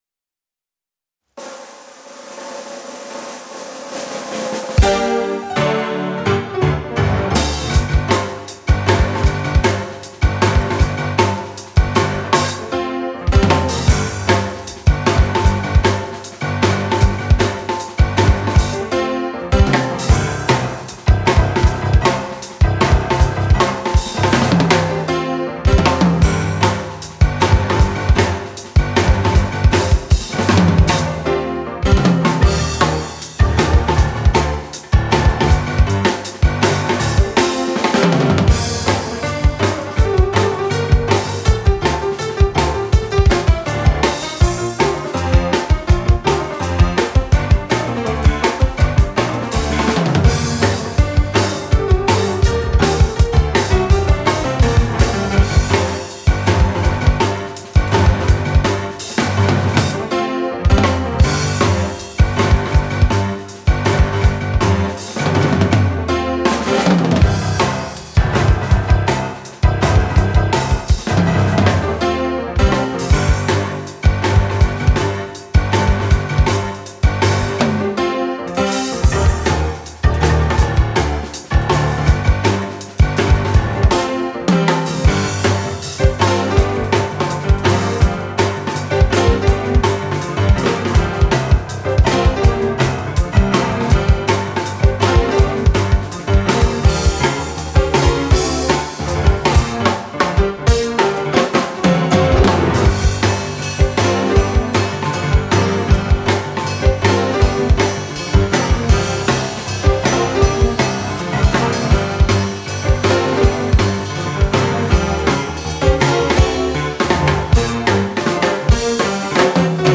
jazz piano/ensemble
Rehearsal Recording
piano
drums
Konstanz